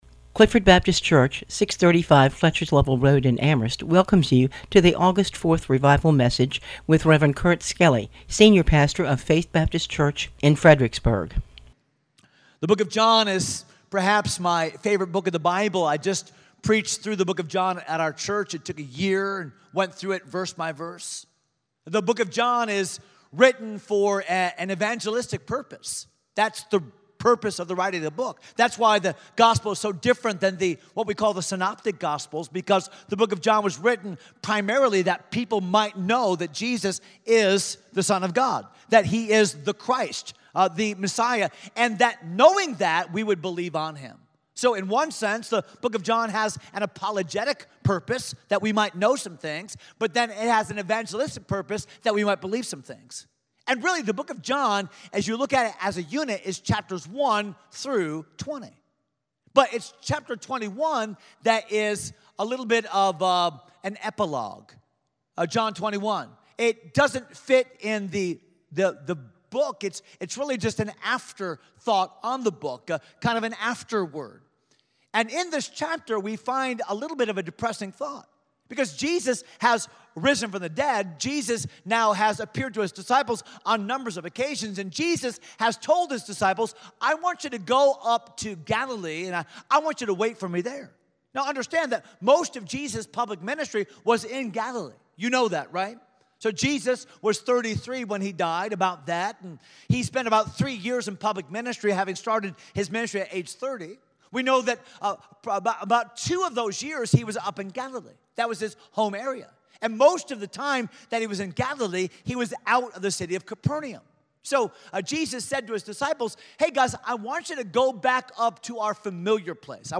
Clifford Baptist Revival Service